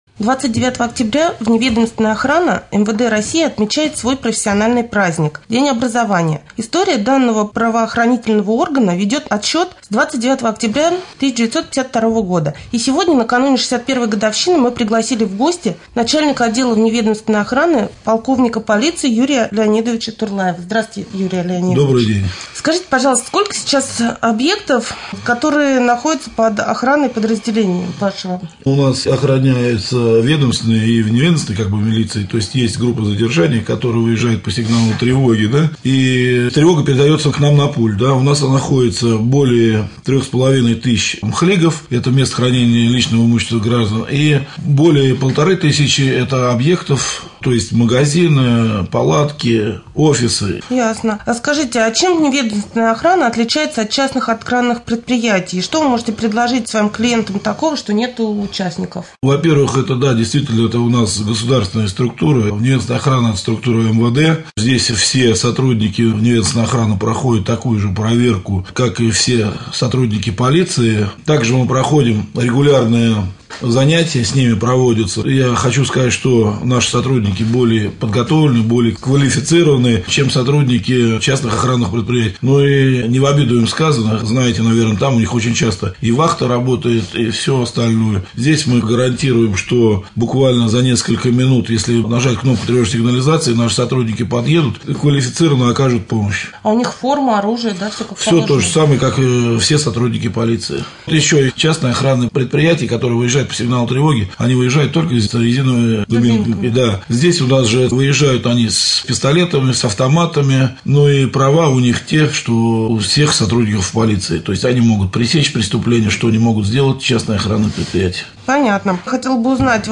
29.10.2013г. в эфире раменского радио - РамМедиа - Раменский муниципальный округ - Раменское